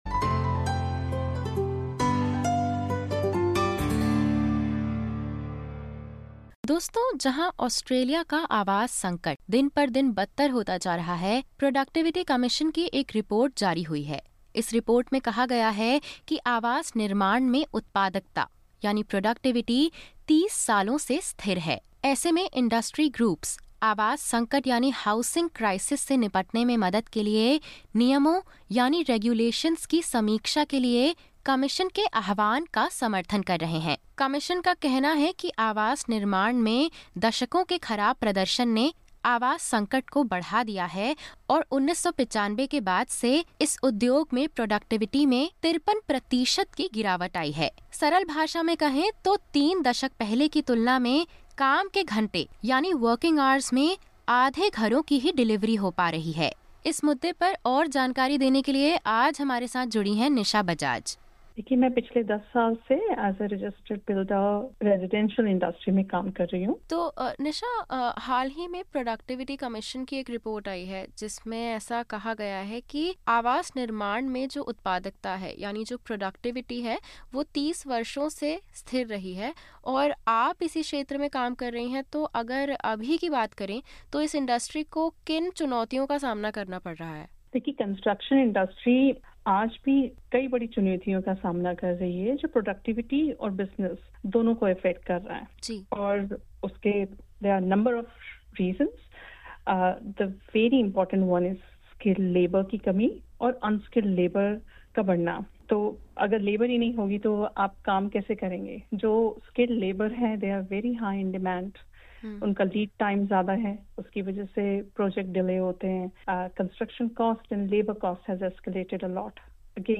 Amid the ongoing housing crisis, the Productivity Commission has released a report revealing a 53 per cent decline in construction sector productivity since 1995, based on a comparison of hours worked and new homes built. To better understand the challenges faced by the industry, SBS spoke with housing expert